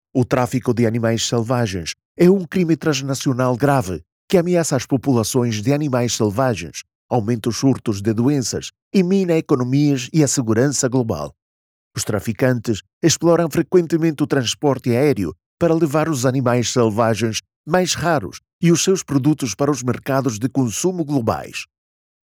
Commercial, Distinctive, Accessible, Versatile, Reliable
Explainer
His voice is often described as fresh, young, calm, warm, and friendly — the “guy next door” — ideal for commercials, audiobooks, narration, promos, YouTube and educational content, e-learning, presentations, and podcasts.
As a full-time producer, studio owner, and musician, he ensures clean, echo-free, uncompressed audio, delivered in any format.